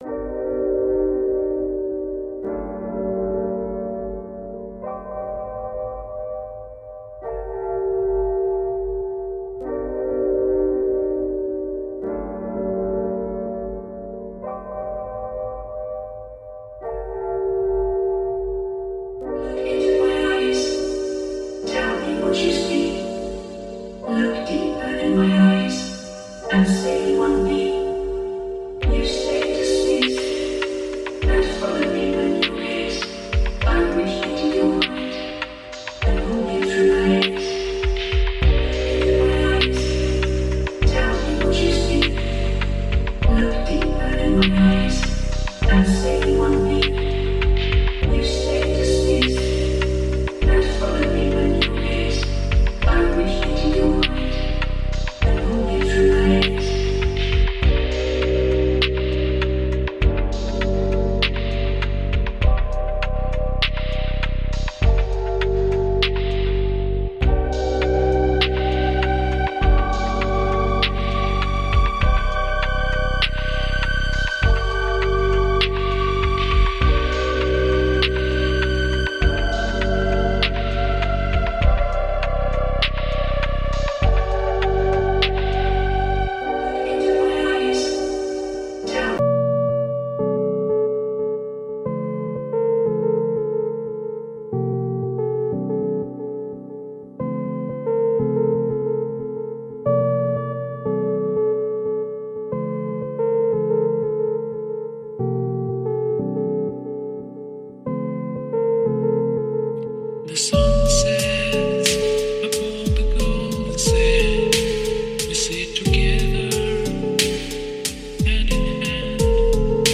Muzyka dostarcza znaczące doznania odprężenia i harmonii.